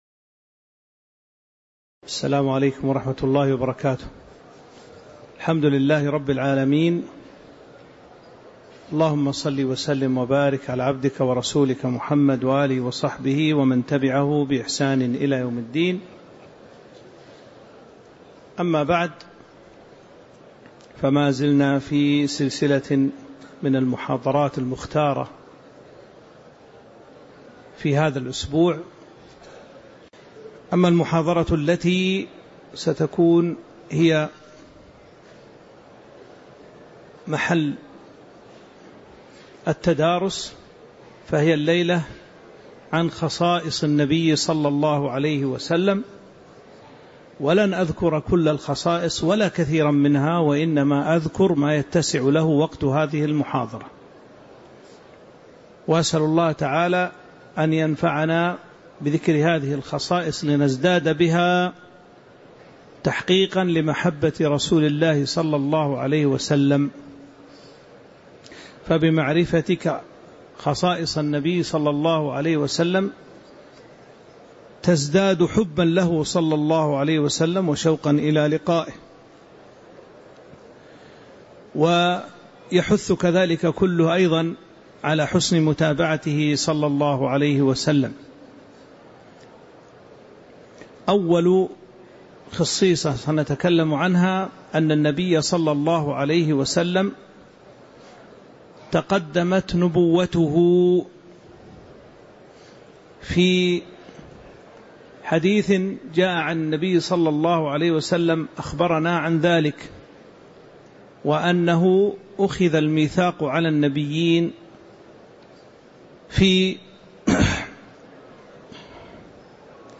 تاريخ النشر ١٦ جمادى الآخرة ١٤٤٦ هـ المكان: المسجد النبوي الشيخ